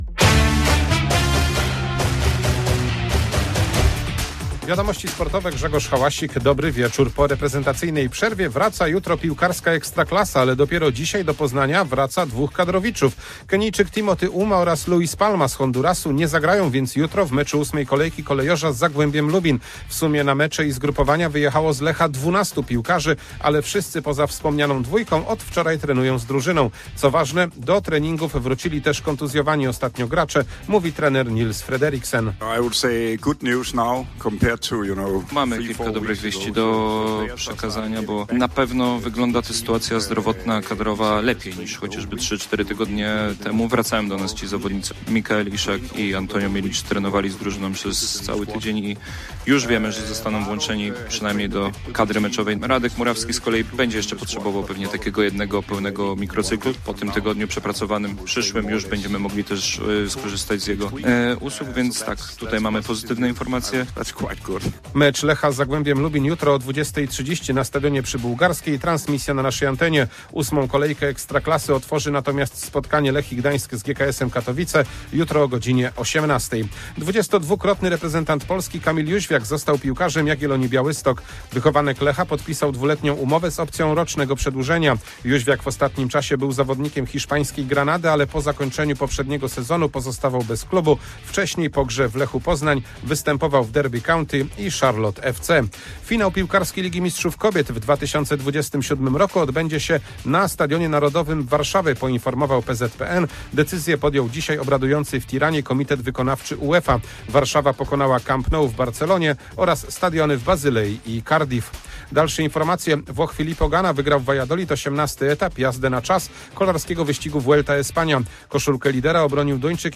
11.09.2025 SERWIS SPORTOWY GODZ. 19:05